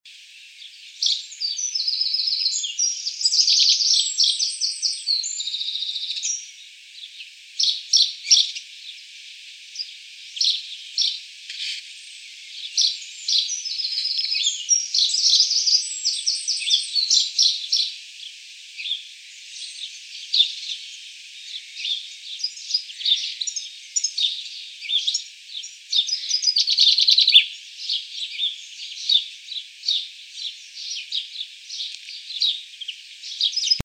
Eurasian Chaffinch (Fringilla coelebs)
Sex: Male
Life Stage: Adult
Location or protected area: Cambridge
Condition: Wild
Certainty: Photographed, Recorded vocal